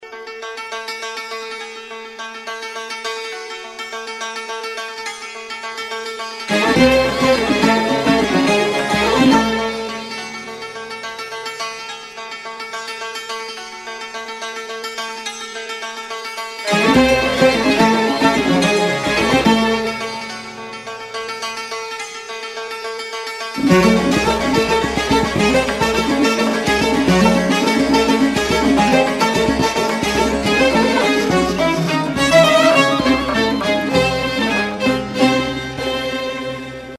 زنگ موبایل ملایم موبایل(سنتی بی کلام)